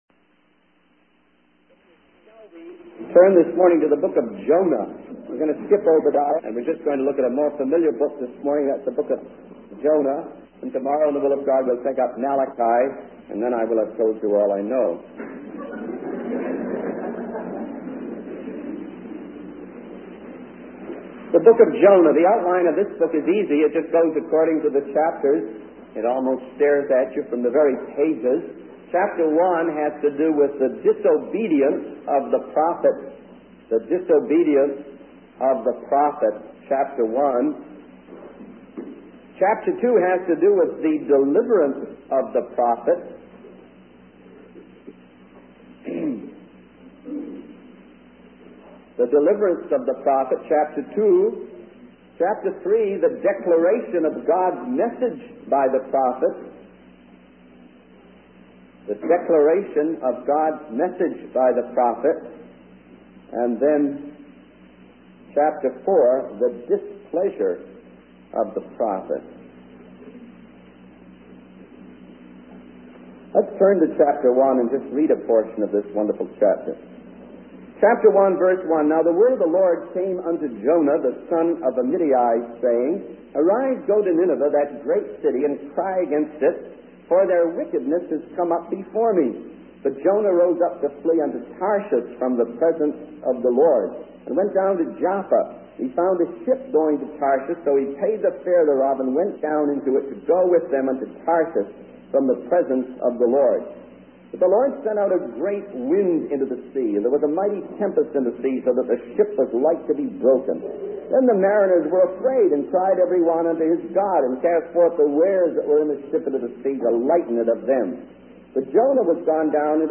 In this sermon, the speaker emphasizes the importance of following God's leading and being devoted to the Savior. He compares the opportunity to work and earn money to the opportunity to change lives and save souls.